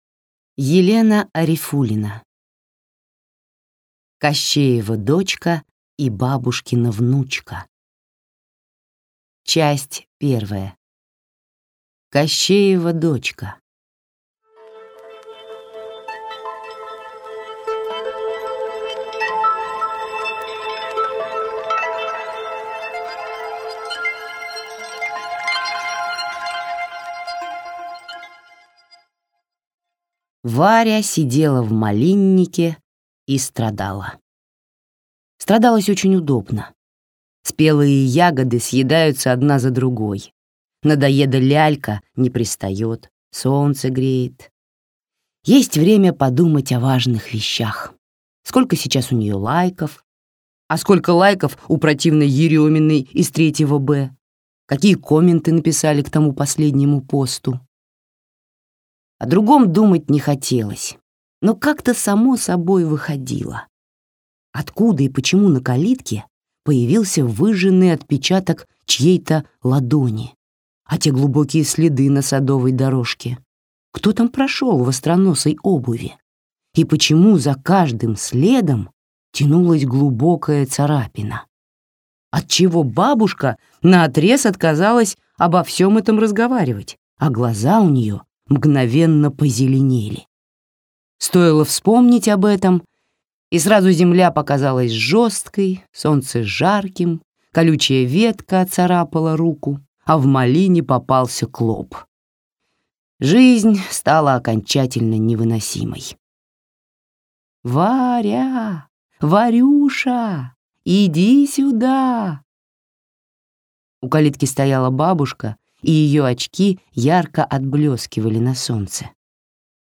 Аудиокнига Кощеева дочка и бабушкина внучка | Библиотека аудиокниг